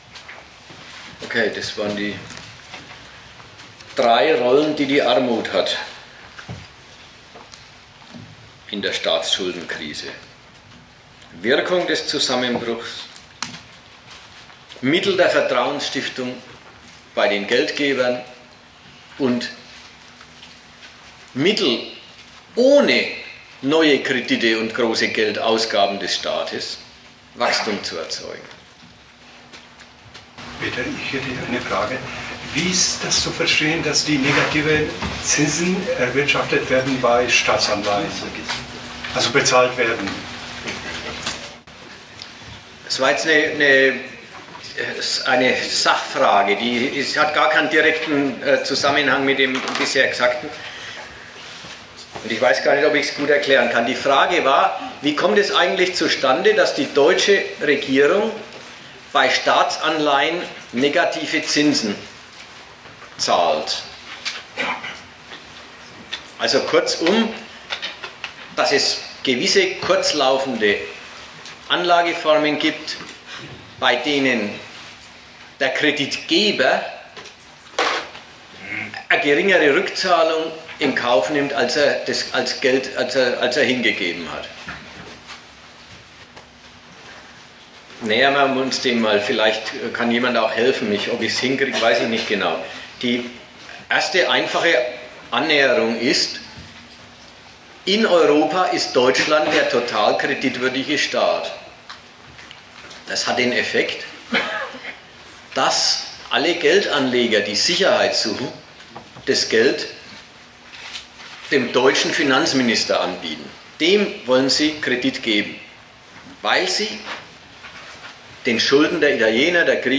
Datum 15.03.2012 Ort Nürnberg Themenbereich Staatenkonkurrenz und Imperialismus Veranstalter Sozialistische Gruppe Dozent Gastreferenten der Zeitschrift GegenStandpunkt In Griechenland, einem Mitglied des stärksten Wirtschaftsblocks des 21.